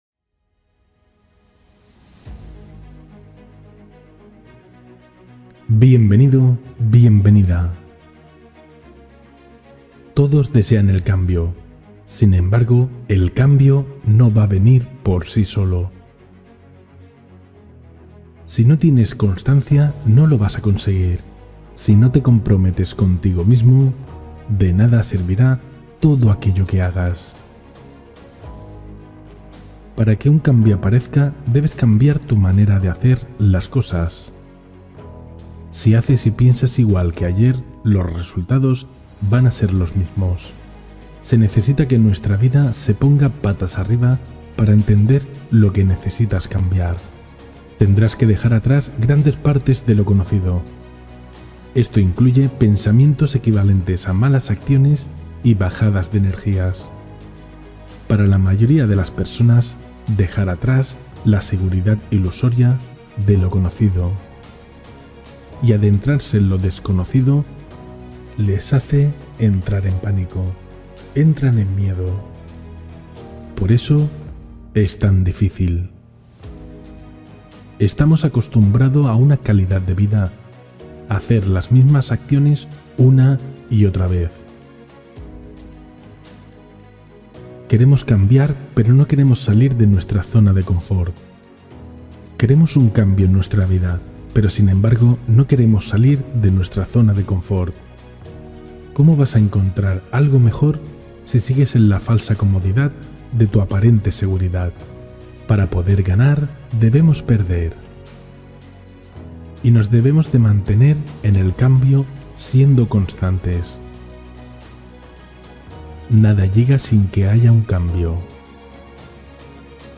Descubre tu misión de vida con esta meditación guiada – Día 8